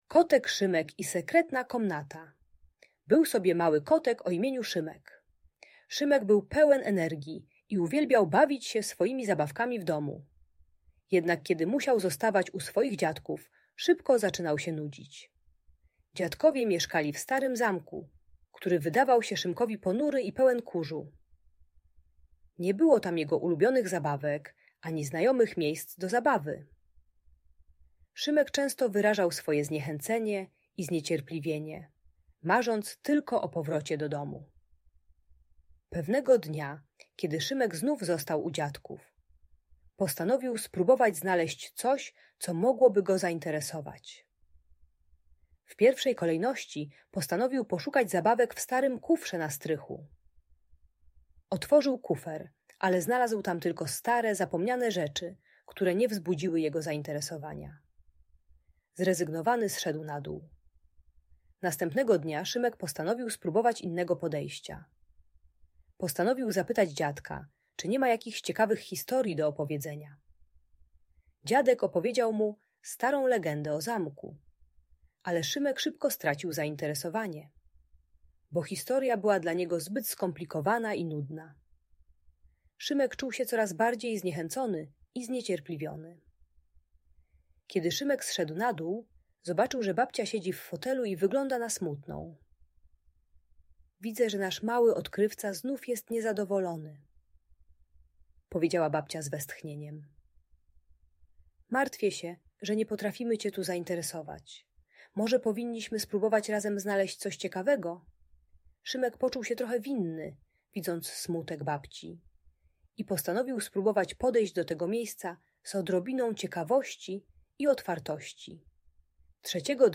Opowieść o Kocie Szymku i Sekretnej Komnacie - Audiobajka